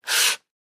in_sandpaper_stroke_02_hpx
Wood being sanded by hand. Tools, Hand Wood, Sanding Carpentry, Build